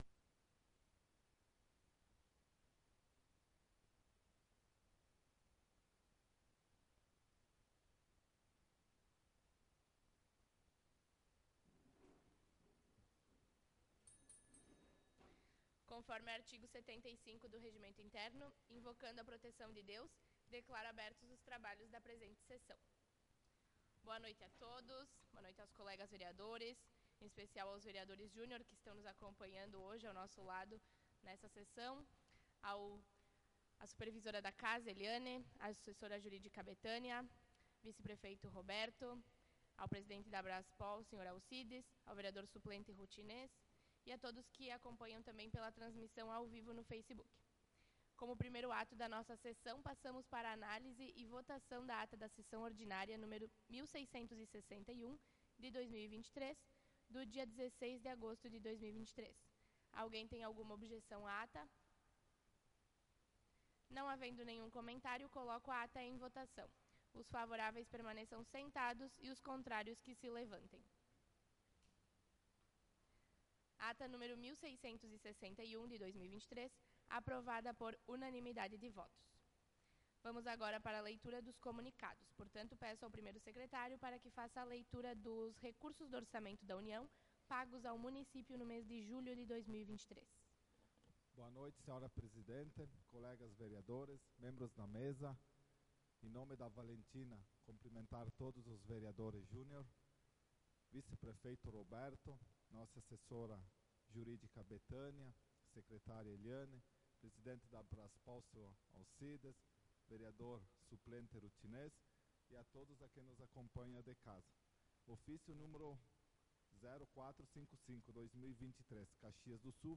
Sessão Ordinária do dia 23/08/2023